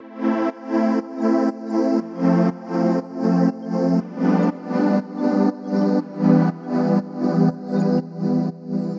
Пример работы pumper во вложении, "качает" звук может можно такое в Ableton сделать, каким нибудь эффектом Вложения Pad pumper.wav Pad pumper.wav 2,3 MB · Просмотры: 205